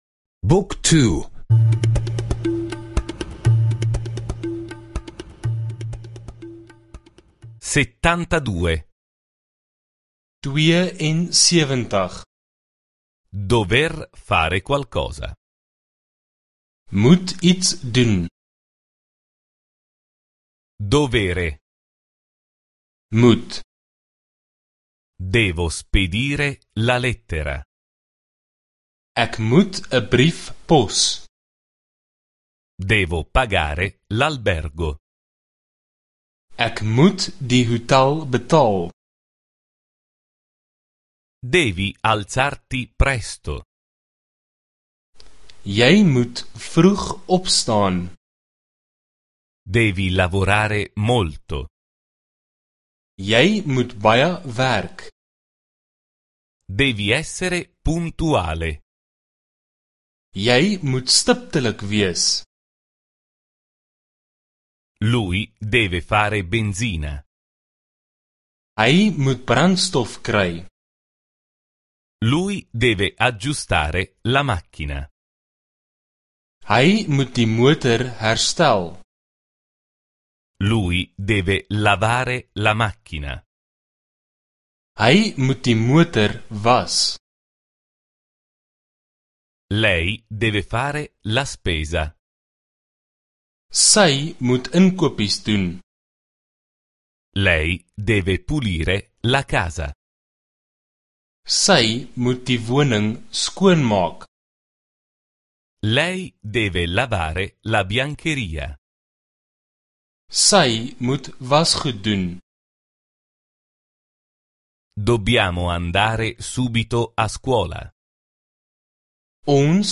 Audio corso afrikaans — ascolta online